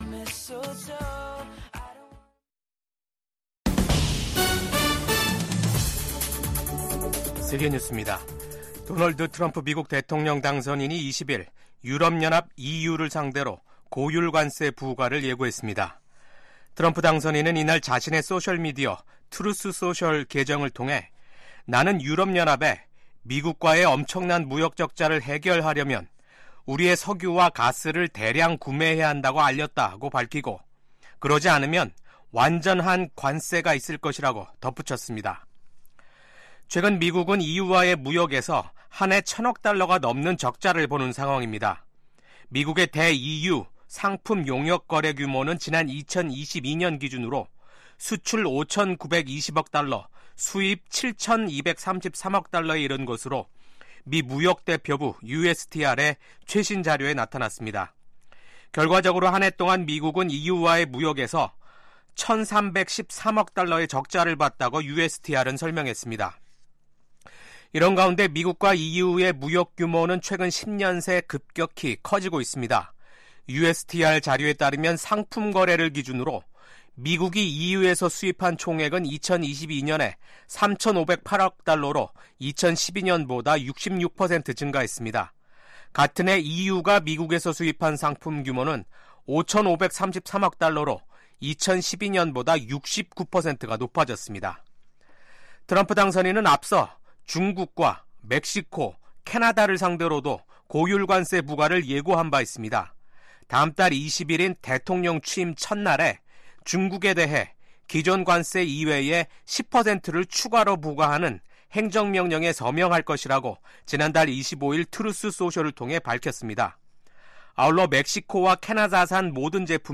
VOA 한국어 아침 뉴스 프로그램 '워싱턴 뉴스 광장'입니다. 미국 국무부는 우크라이나 전쟁에 북한군을 투입한 김정은 국무위원장의 국제형사재판소(ICC) 제소 가능성과 관련해 북한 정권의 심각한 인권 유린 실태를 비판했습니다.